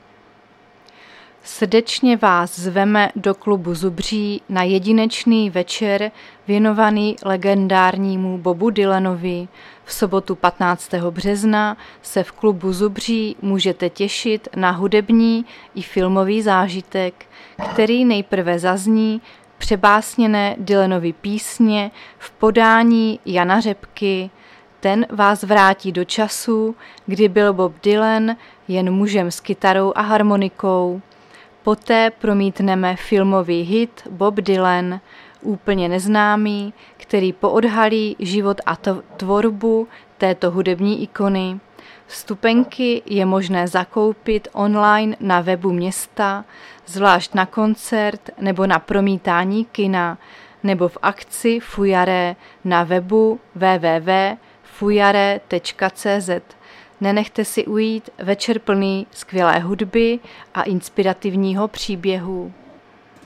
Záznam hlášení místního rozhlasu 12.3.2025
Zařazení: Rozhlas